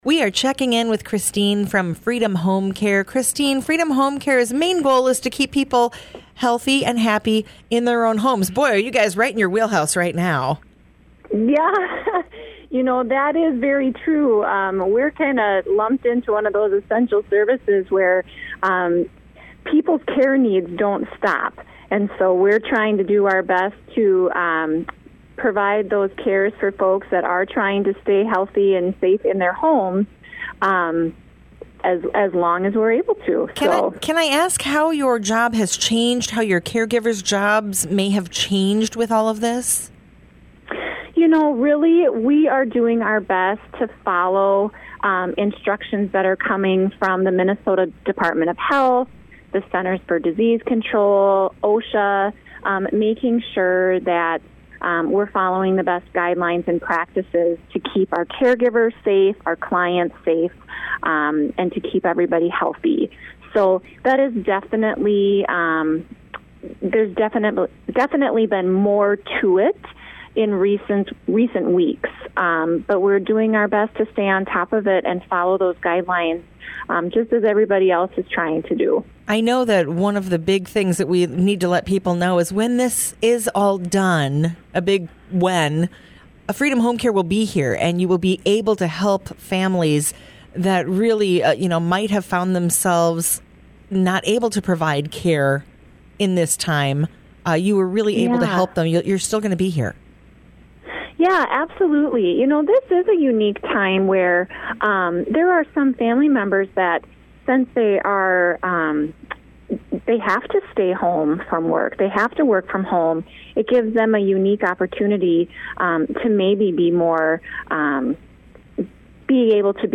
Live interviews are aired on Thursday mornings between 9:00 a.m. to 9:20 a.m. on 100.5 FM.